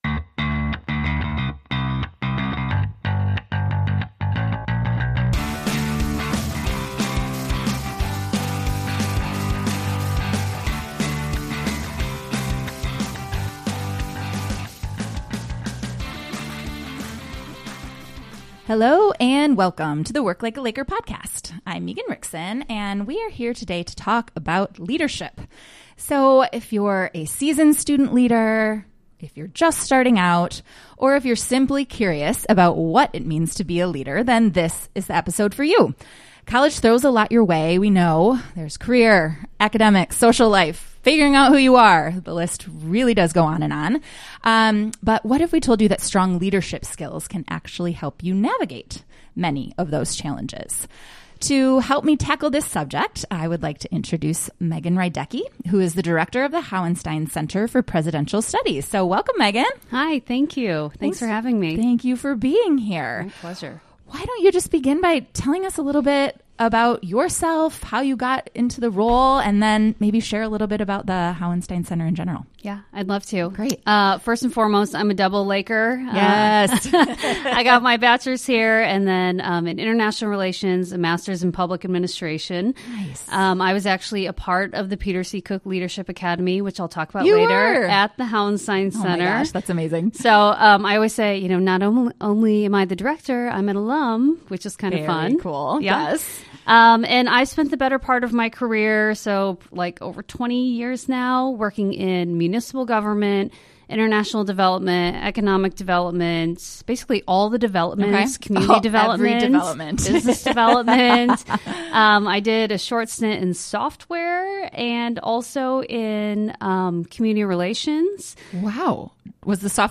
Join GVSU career advisors as they tackle pertinent career-related topics - providing tips, advice and strategies for discovering, preparing for, and implementing careers.